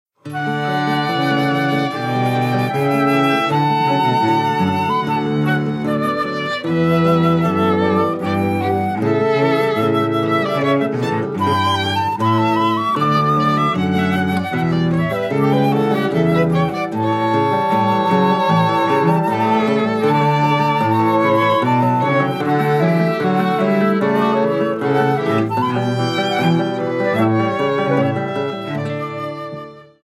GRABADO EN planet estudio, Blizz producciónes
SOPRANO
VIOLONCELLO SOLO Y ENSAMBLES